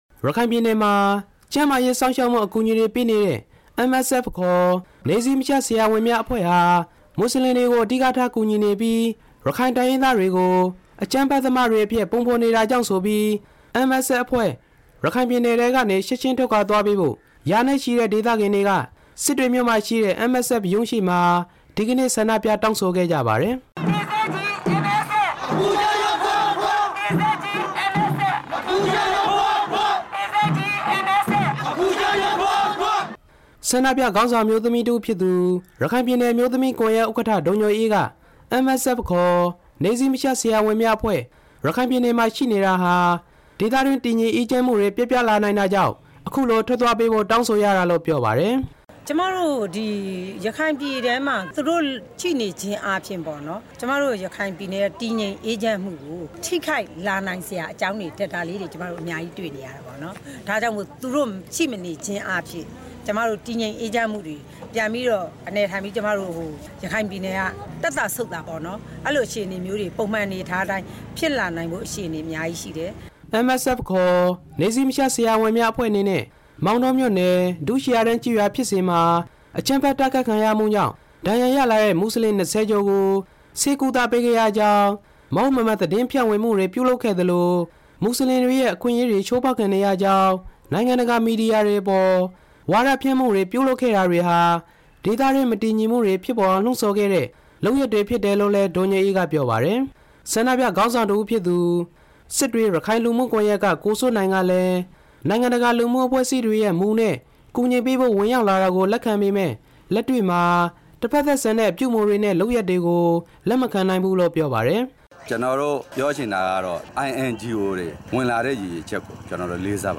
ဒီဆန္ဒပြပွဲအကြောင်း တင်ပြချက်